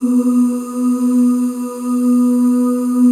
B2 FEM OOS.wav